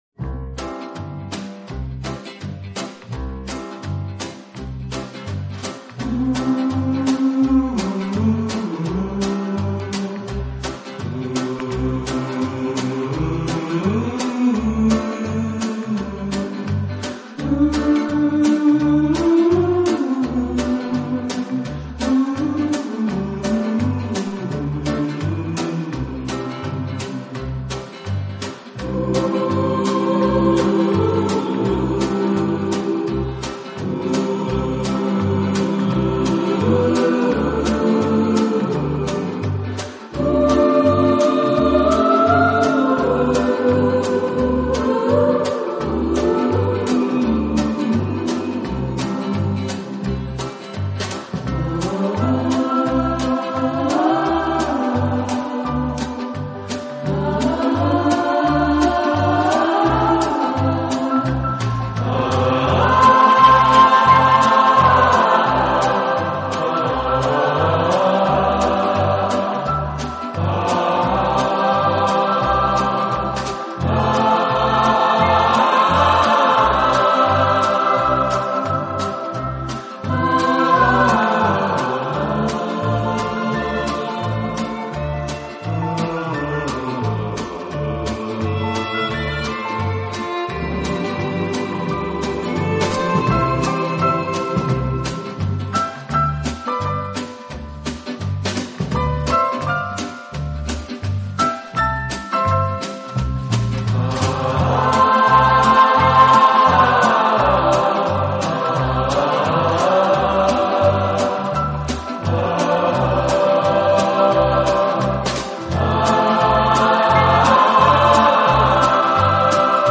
【顶级轻音乐】
此外，这个乐队还配置了一支训练有素，和声优美的伴唱合唱队。
引力和动人心弦的感染力。